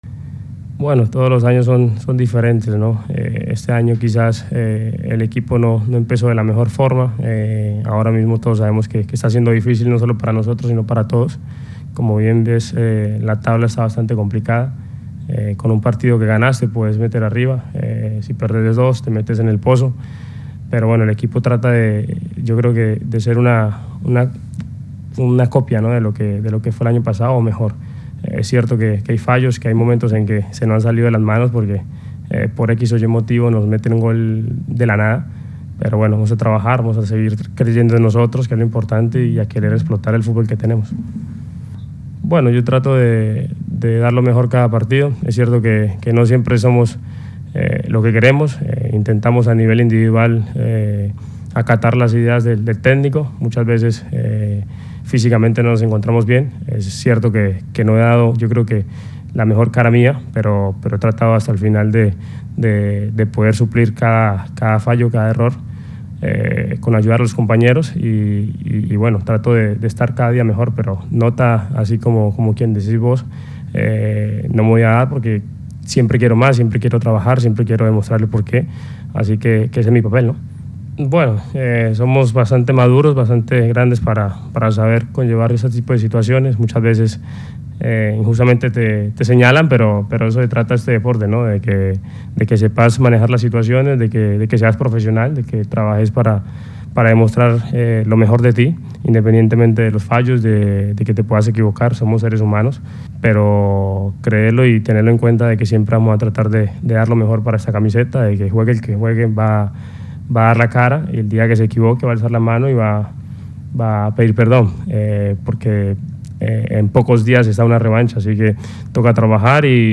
“Trato de dar el máximo en cada partido. Es obvio que siempre se cometen fallos a nivel individual. Es normal que el agotamiento se demuestre cuando tienes una suma de partidos en tan poco tiempo. Trabajamos para estar bien y el que no esté bien que levante la mano, pero es cierto que no he dado mi mejor cara”, indicó Murillo en rueda de prensa.